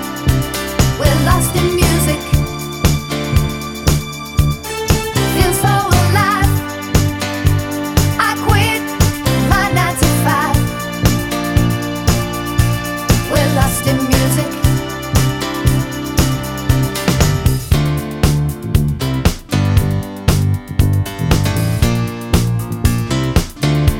no Backing Vocals Disco 4:27 Buy £1.50